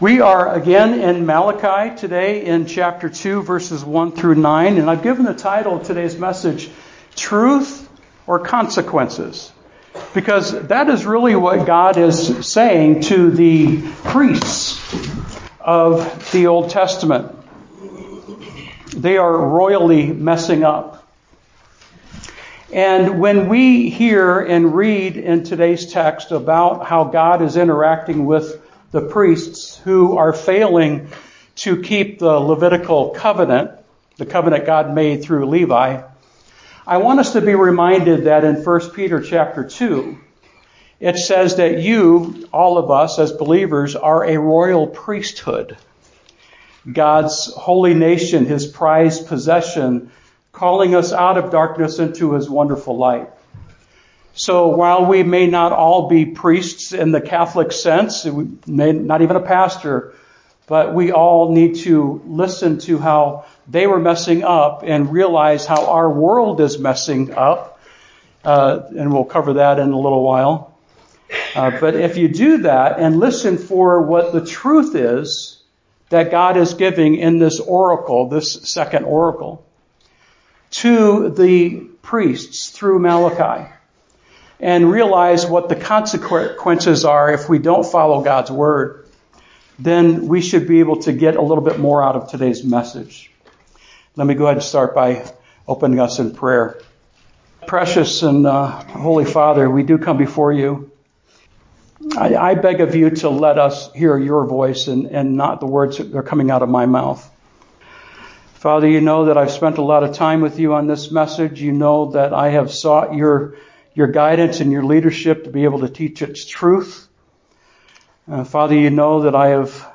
Sermon Title: Truth or Consequences